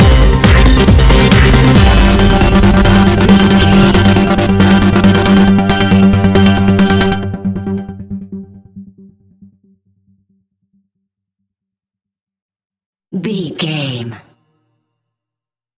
Fast
drum machine
acid house
synthesizer
electronic
uptempo
instrumentals
synth drums
synth leads
synth bass